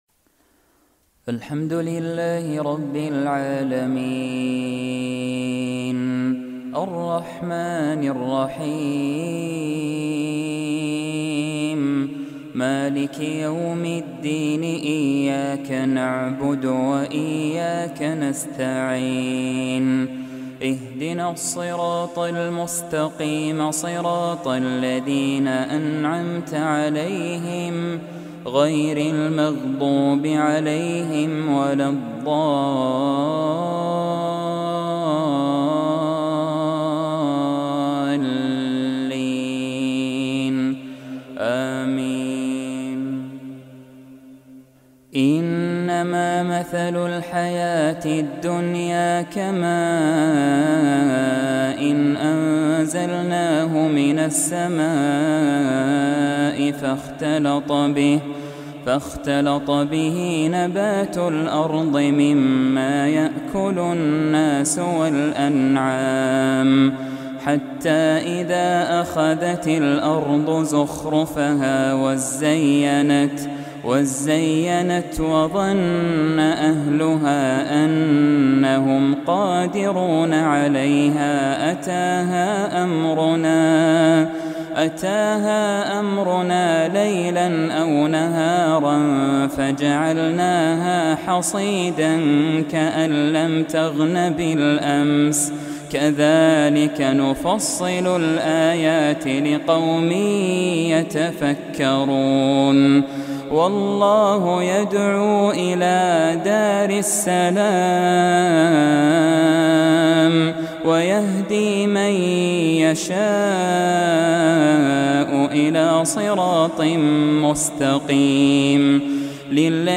عشائية من سورة يونس